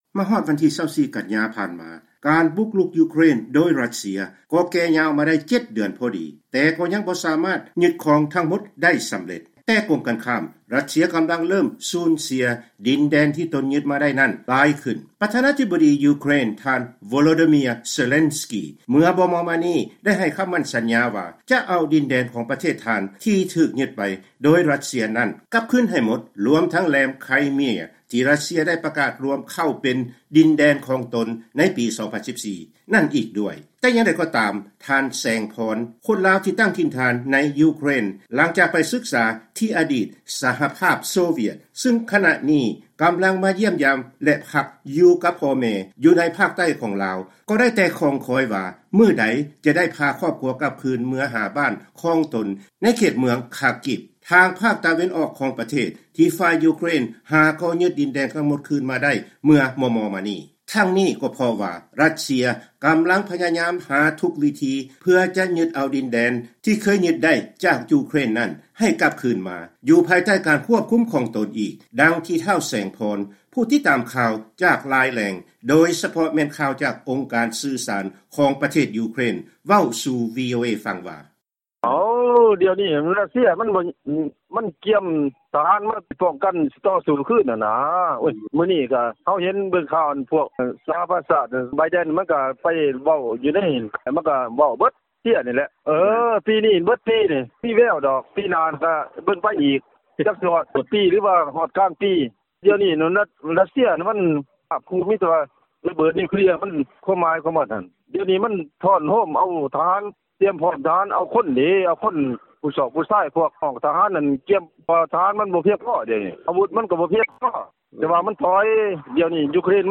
ຍິ່ງມາຮອດວັນຄົບຮອບ 7 ເດືອນ ແຫ່ງການບຸກລຸກ ຂອງຣັດເຊຍ, ພວກເຂົາເຈົ້າ ກໍຍິ່ງຄິດຮອດ ຖິ່ນຖານບ້ານຊ່ອງ ຂອງເຂົາເຈົ້າຫລາຍຂຶ້ນ. ວີໂອເອ ຈະນໍາເອົາການສໍາພາດ ກັບຊາວອົບພະຍົບຢູເຄຣນເຊື້ອ ສາຍລາວທ່ານນຶ່ງ ເພື່ອຖາມເຖິງຄວາມຮູ້ສຶກ ແລະທັດສະນະຂອງທ່ານກ່ຽວກັບ ສົງຄາມນັ້ນ, ຊຶ່ງນັກຂ່າວຂອງພວກເຮົາຈະນໍາມາສະເໜີທ່ານໃນອັນດັບຕໍ່ໄປ.